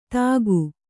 ♪ tāgu